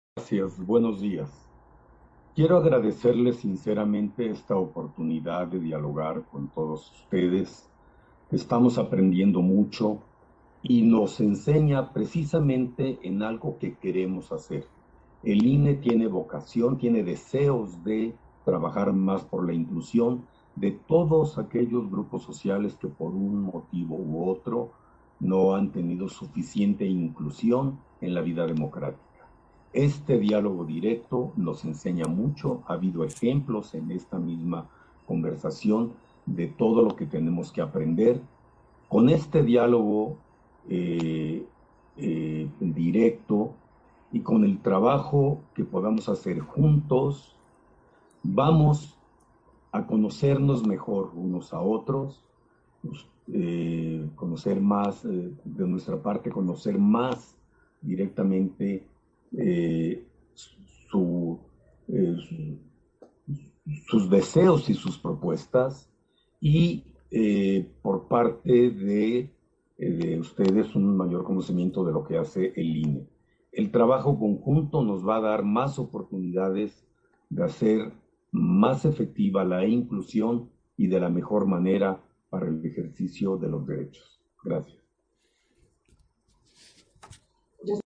Intervenciones de Consejeras y Consejeros del INE